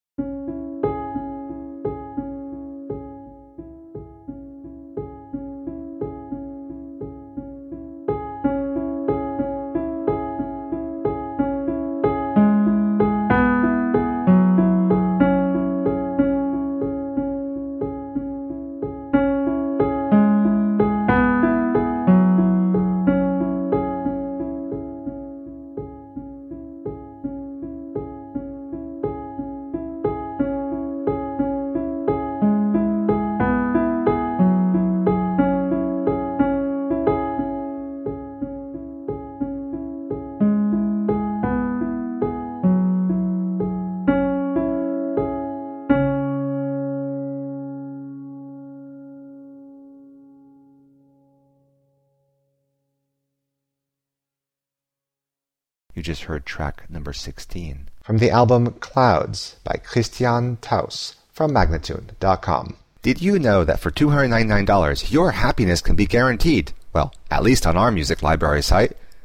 Collections of melancholic and minimalistic soundscapes.
A moodful collection of mainly solo piano pieces.